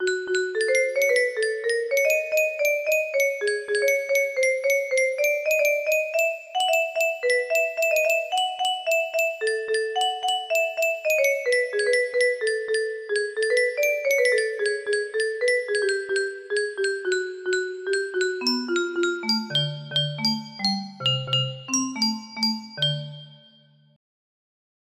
20228 music box melody